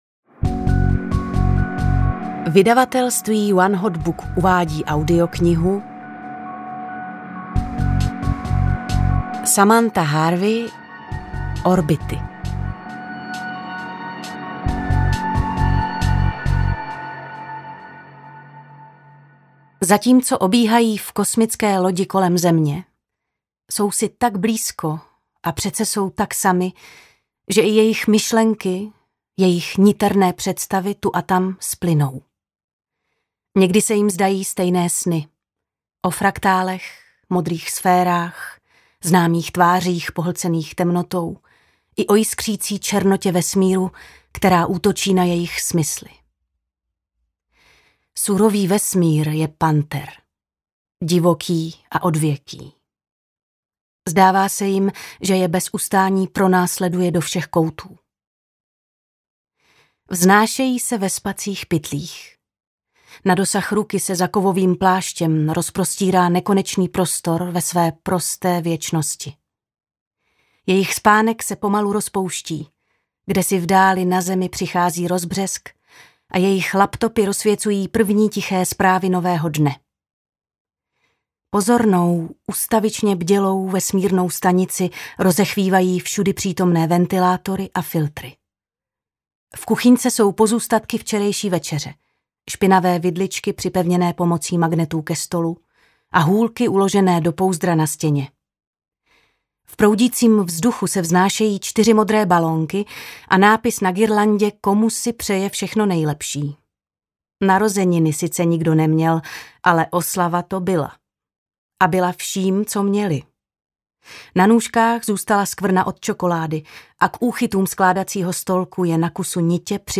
Orbity audiokniha
Ukázka z knihy